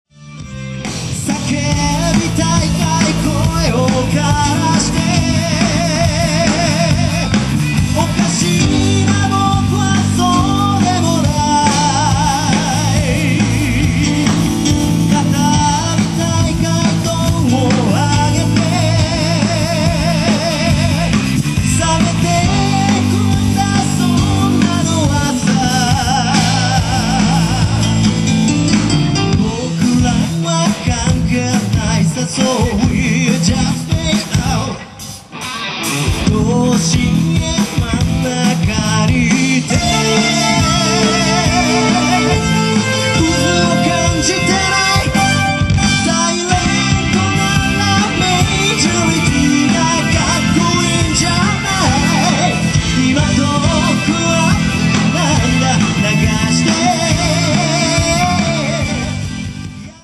兵庫教育大学学園祭 嬉望祭